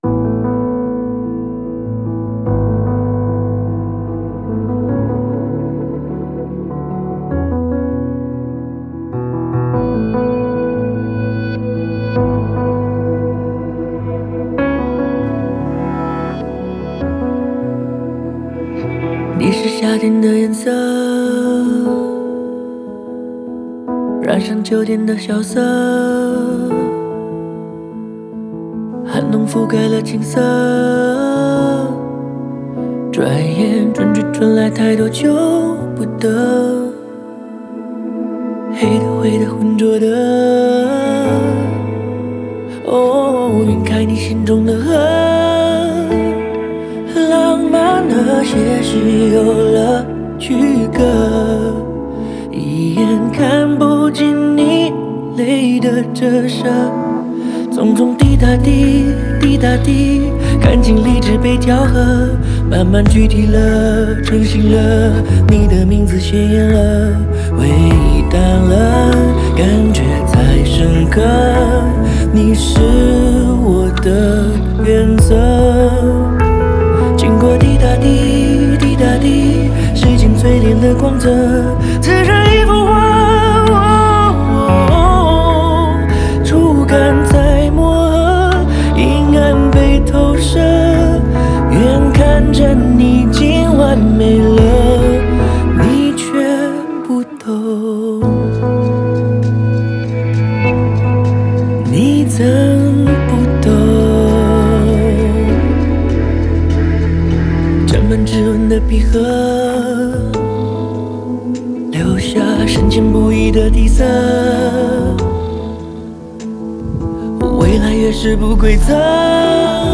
音乐风格：流行